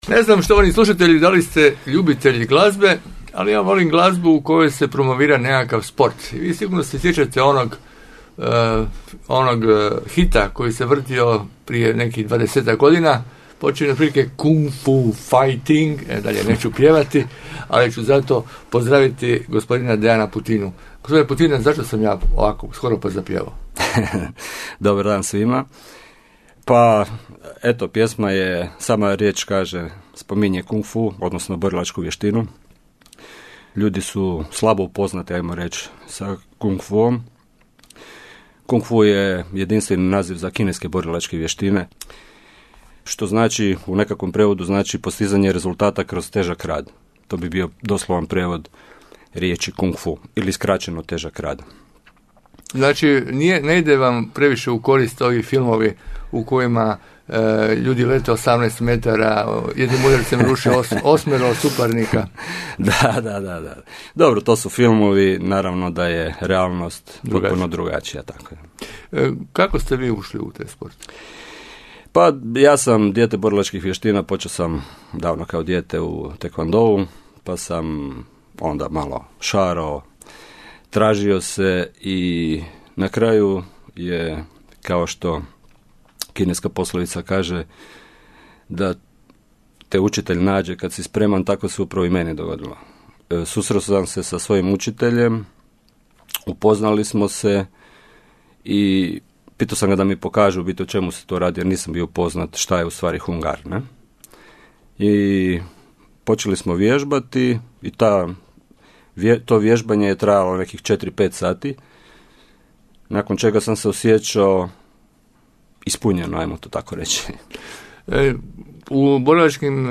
Gostovanje u emisiji Hrvatskog radio Pula